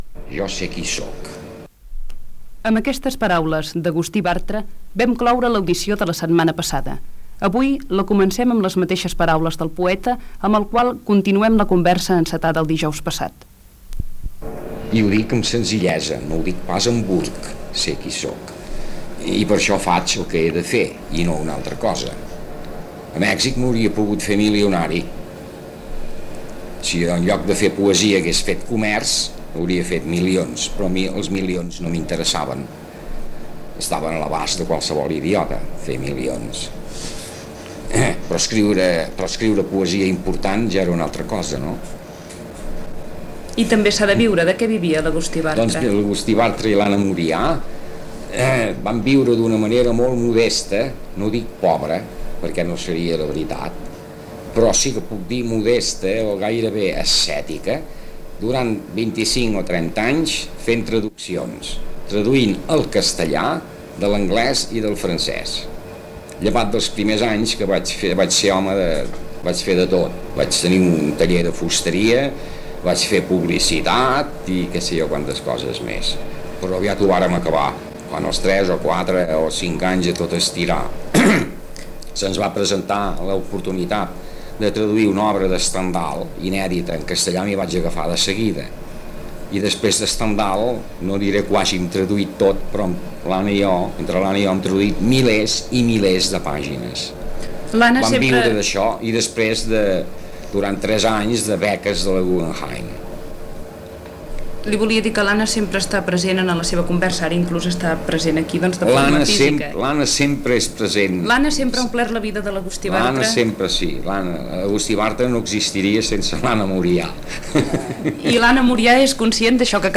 Entrevista al poeta Agustí Bartra qui parla de la seva vida a l'exili i de la militància política. També llegeix alguns fragments de la seva obra